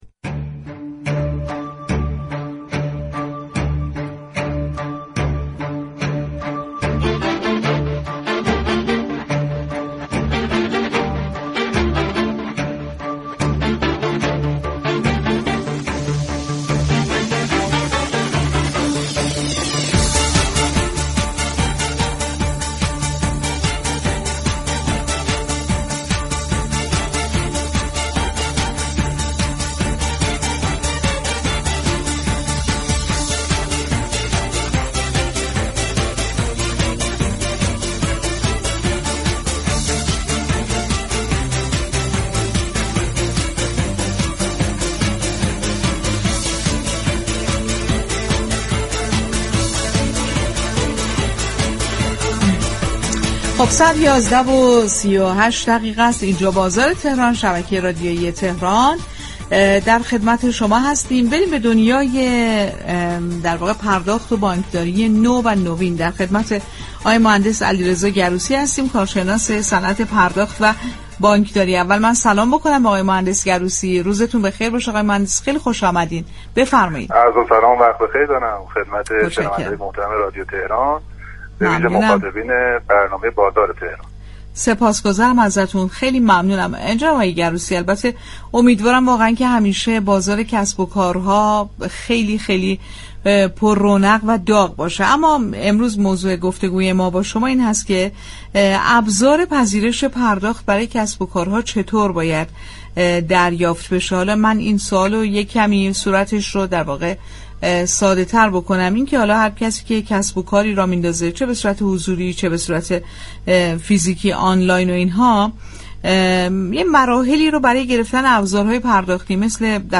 یك كارشناس صنعت پرداخت در گفتگو با رادیو تهران، مراحل دریافت ابزارهای پرداختی مانند كارتخوان و درگاه اینترنتی، مدارك موردنیاز و الزامات قانونی برای اشخاص حقیقی و حقوقی تشریح شد.